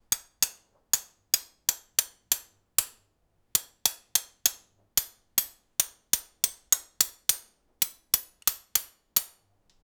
repair-fixing-tools-k5gcgfw6.wav